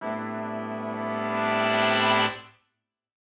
Index of /musicradar/gangster-sting-samples/Chord Hits/Horn Swells
GS_HornSwell-Amin+9sus4.wav